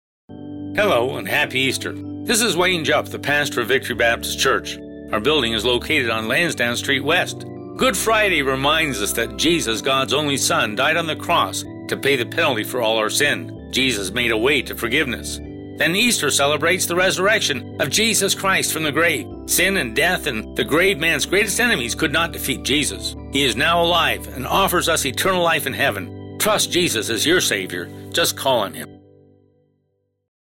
Newspaper Ad Easter - Peterborough This Week Radio Ad